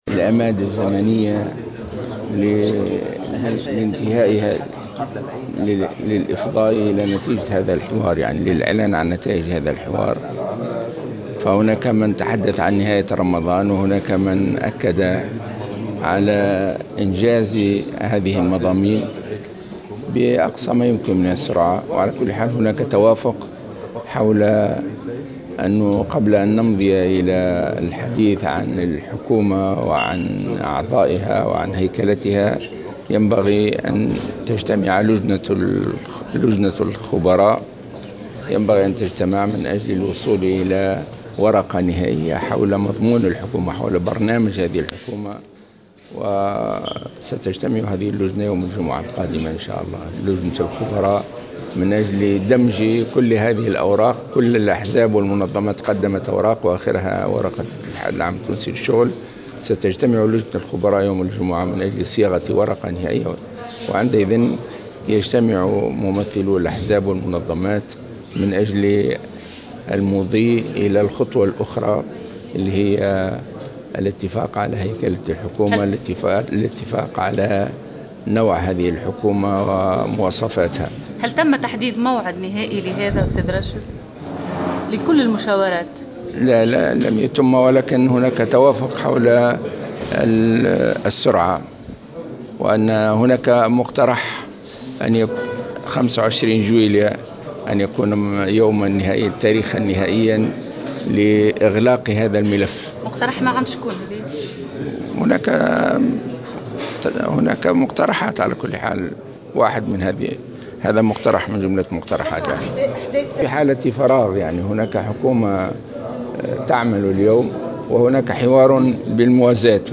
وقال في تصريحات صحفية على هامش مشاركته في اجتماع أشرف عليه رئيس الجمهورية إن هناك مقترحا لإغلاق هذا الملف يوم 25 جويلية كتاريخ نهائي لإغلاق هذا الملف.